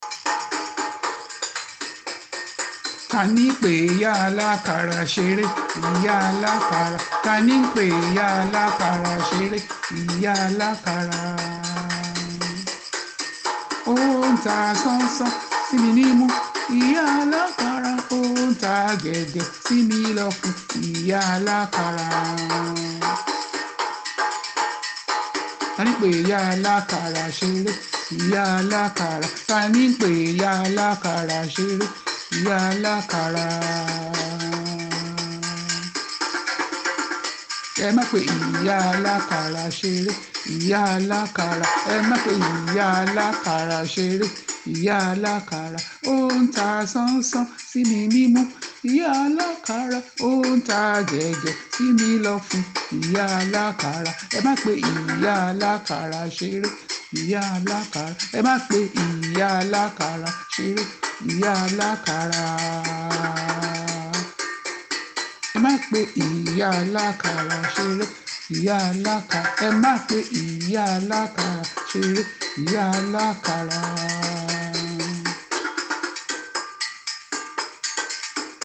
Immediately Ijapa started to drum, and to sing a very sweet, a very melodious, and diverting song that went as follows: